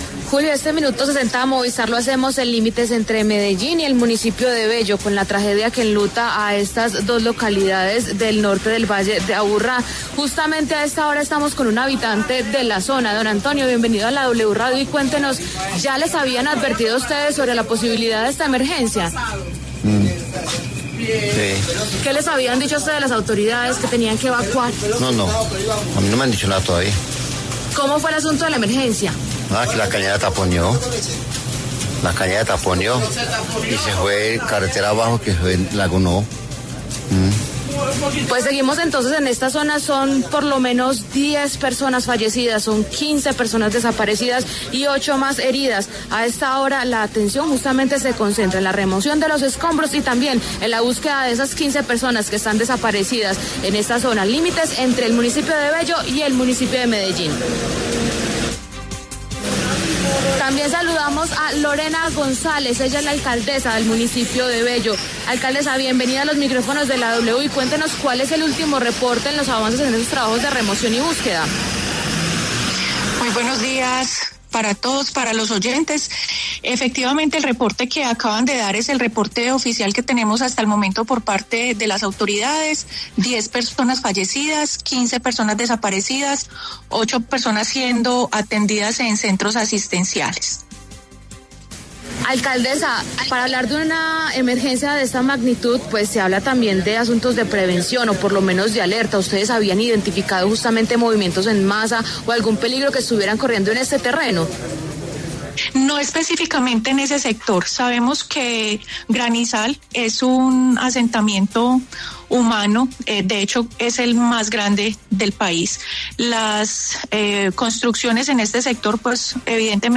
Lorena González, alcaldesa de Bello, habló en La W y entregó detalles sobre los trabajos de remoción y búsqueda.